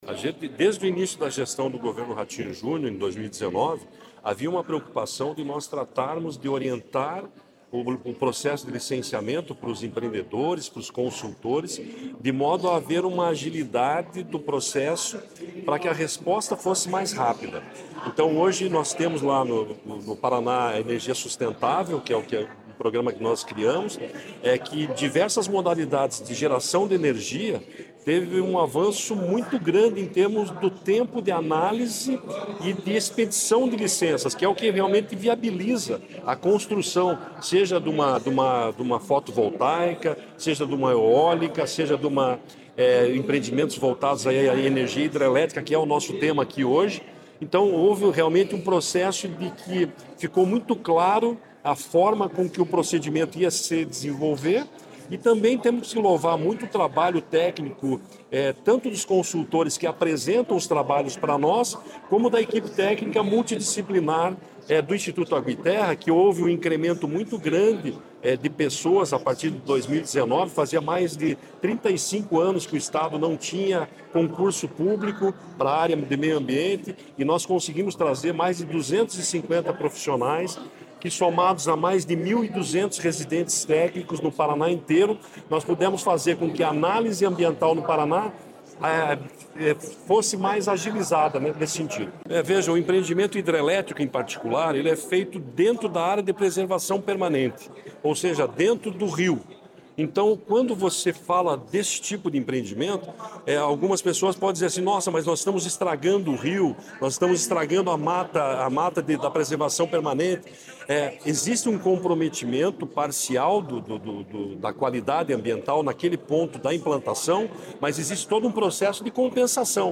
Sonora do presidente do IAT, Everton Souza, sobre a construção de 11 novas PCHs no Paraná